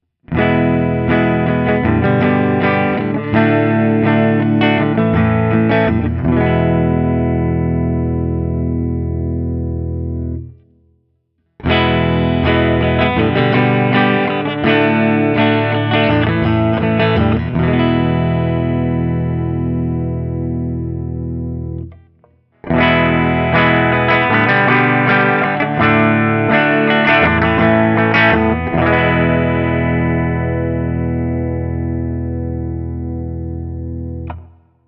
This is an aggressive sounding guitar that’s a bit dark for my tastes, a fact that I attribute to the Guild XR7 pickups and the mahogany body.
Open Chords #2
As usual, for these recordings I used my normal Axe-FX II XL+ setup through the QSC K12 speaker recorded direct into my Macbook Pro using Audacity.
For each recording I cycle through the neck pickup, both pickups, and finally the bridge pickup.